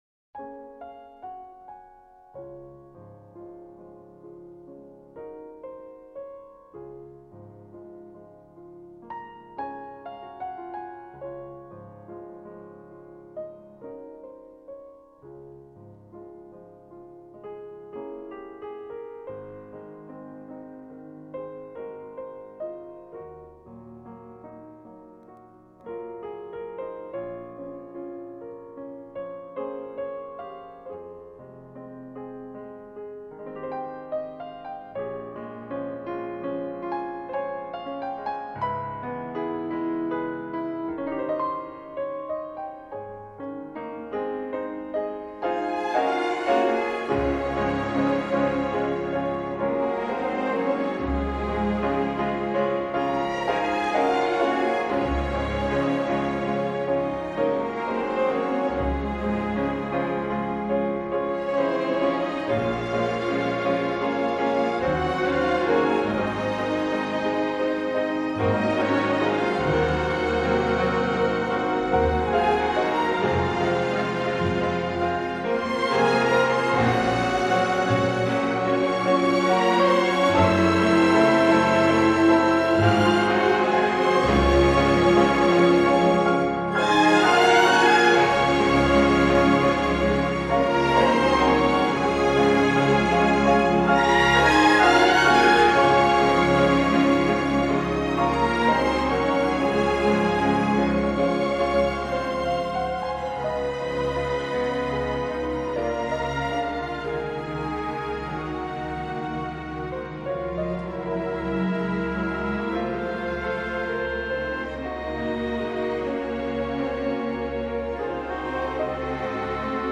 这段荡气回肠，扣人心弦又带着淡淡忧伤的钢琴曲，
响起的提琴声是那么的委婉和凄美,令人思绪万千,
纯音乐